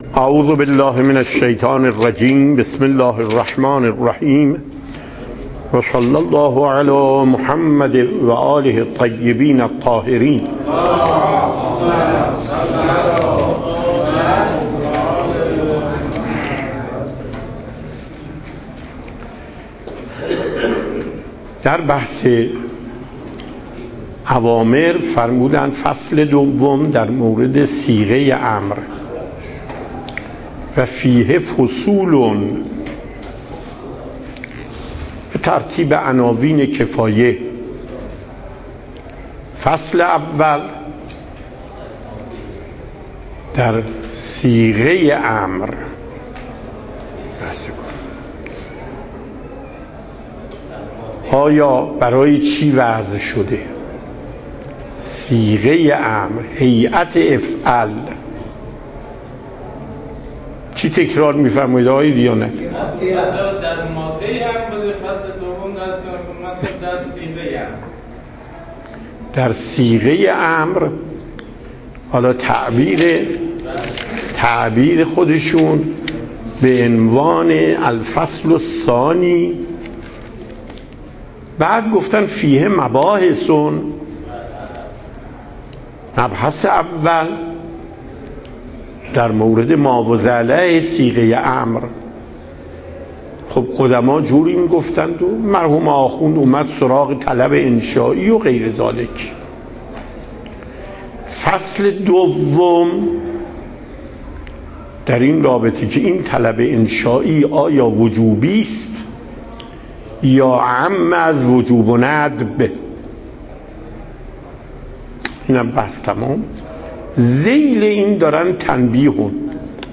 درس اصول آیت الله محقق داماد